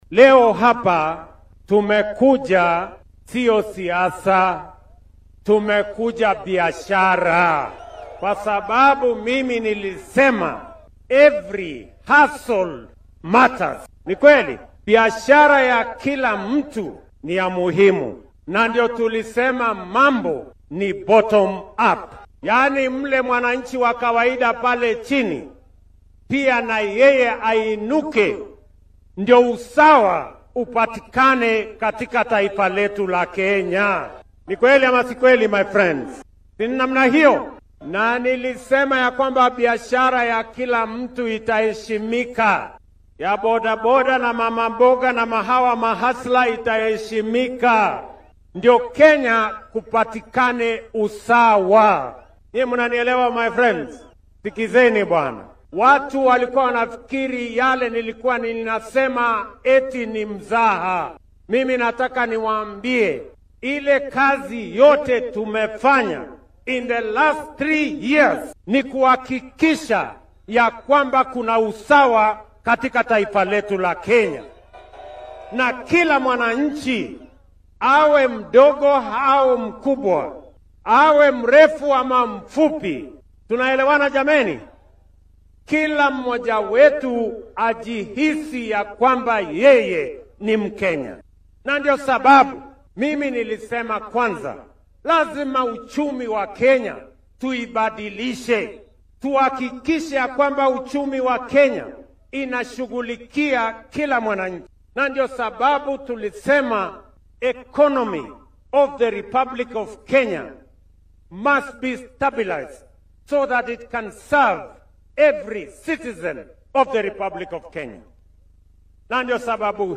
Waxaana uu madaxweynuhu bixiyay agabyo kala duwan si kor loogu soo qaado ganacsatada yar yar sido kalena ay shaqo abuur ugu noqoto Kenyaanka. Mar uu madaxweynaha khudbad u jeedinayay shacabkii ka soo qeyb galay barnaamijkaas ayaa hadaladiisa waxaa ka mid ah.